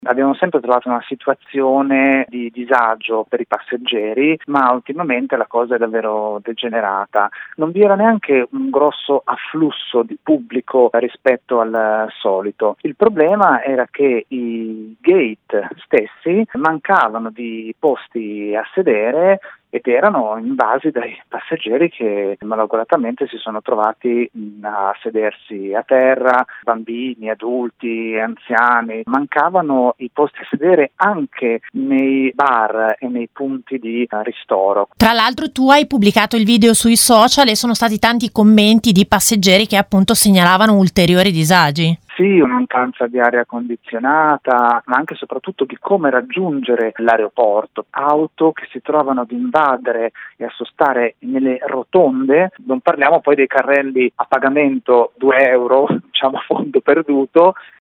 testimonianza